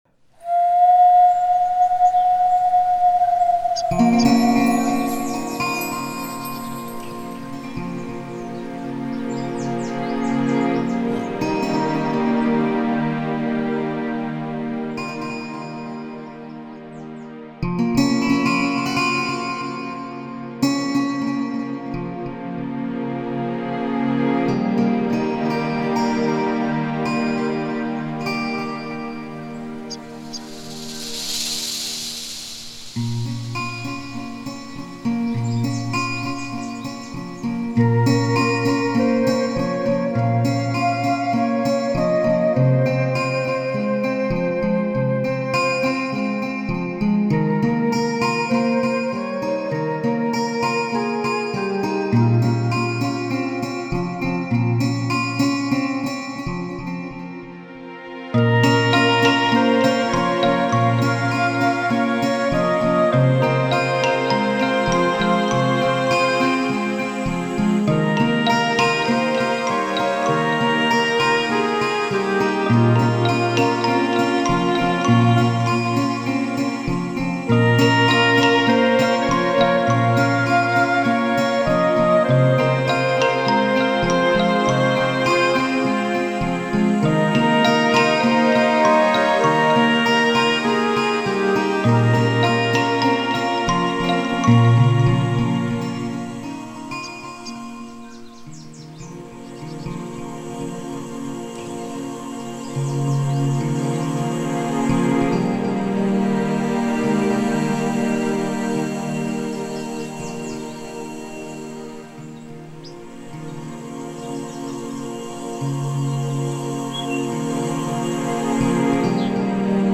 The guy behind it is a big fan of the original Diablo's soundtrack, so I played around with some acoustic-guitar samples to create …